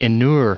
1503_inured.ogg